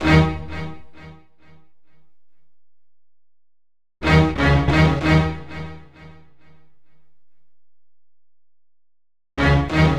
Night Rider - Orchestral Stab.wav